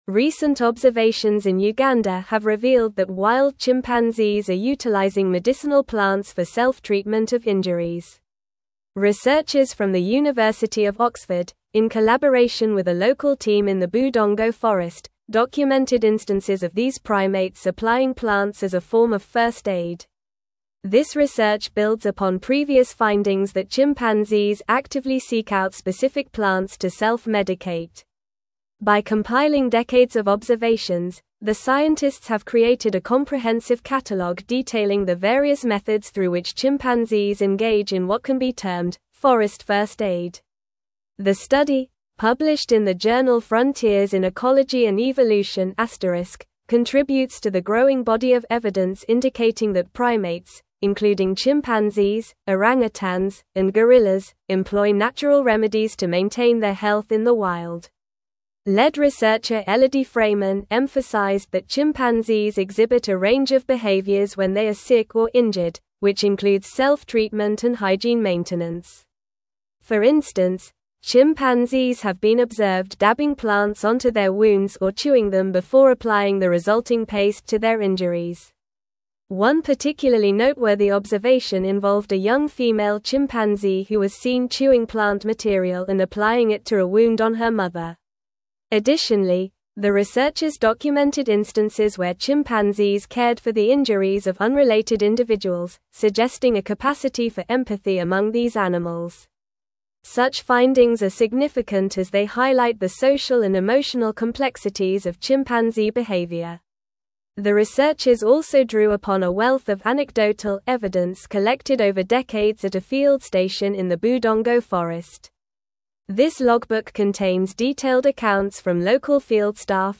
Normal
English-Newsroom-Advanced-NORMAL-Reading-Chimpanzees-Use-Medicinal-Plants-for-Self-Care-in-Uganda.mp3